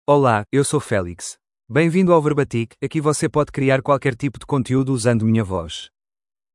MalePortuguese (Portugal)
FelixMale Portuguese AI voice
Voice sample
Male
Felix delivers clear pronunciation with authentic Portugal Portuguese intonation, making your content sound professionally produced.